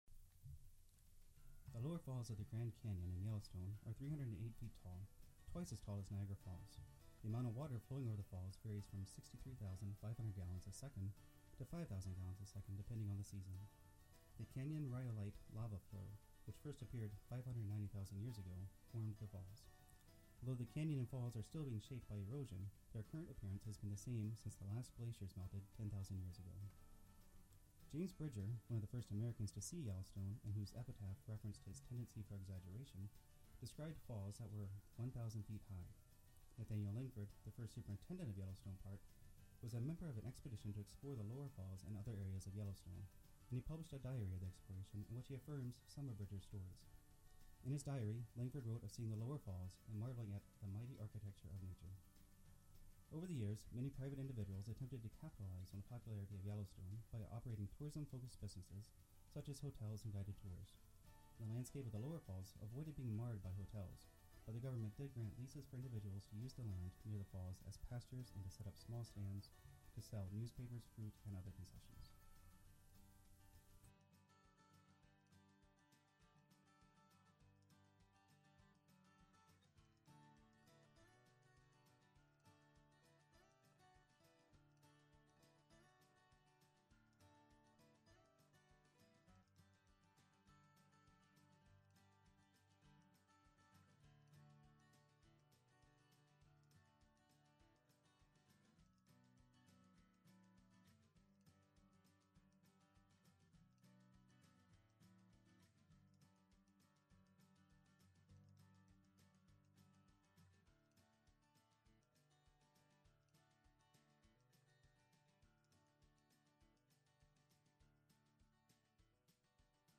Researched, written, and narrated by University of West Florida Public History Student